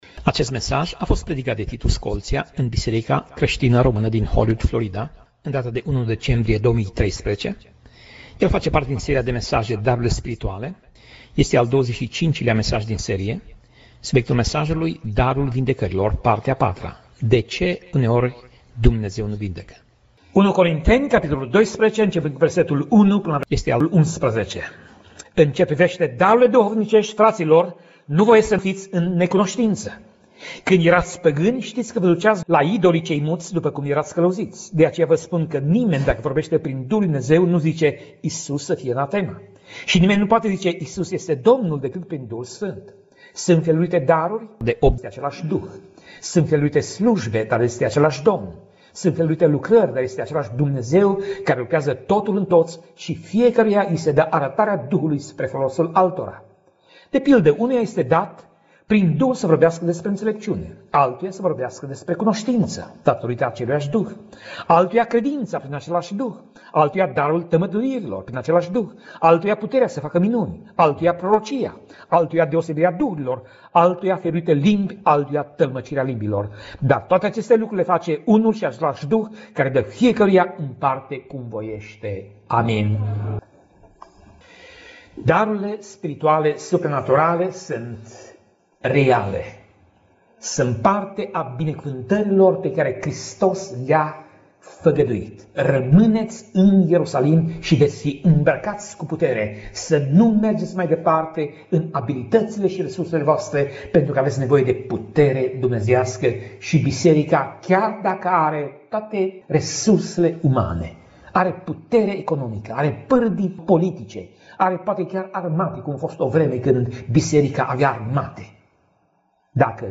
Tip Mesaj: Predica